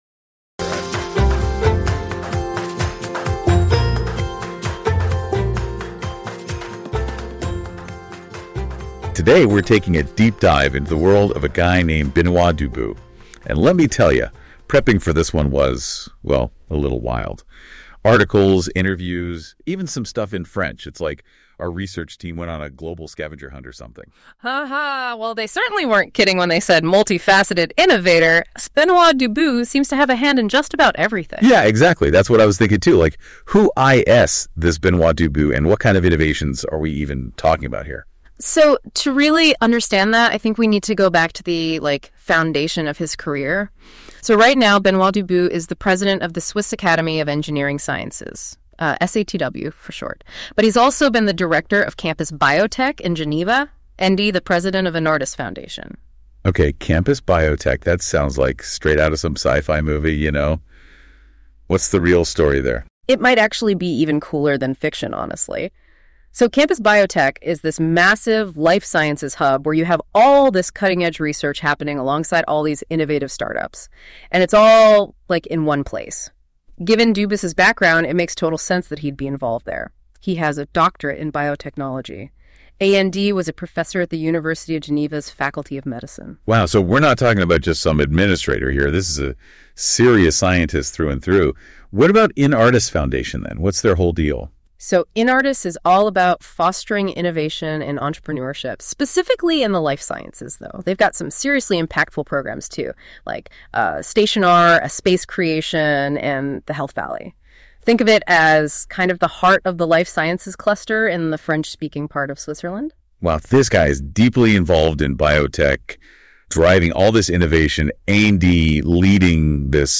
Une conversation dense, vivante, presque… trop parfaite ?
Ce dialogue a été entièrement reconstruit à partir de quelques documents disponibles — et pourtant, l’effet est troublant.